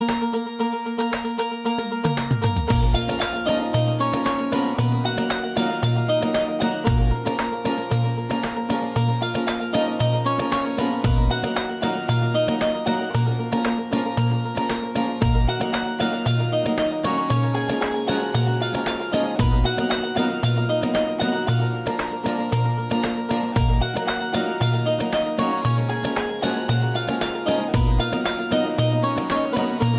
epic and inspiring theme music